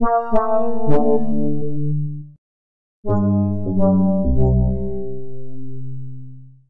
F M Synthesis " 敦敦实实的合成器
描述：在fm合成器中请求一个"dundundun"。
Tag: 逼债 电子 FM 合成器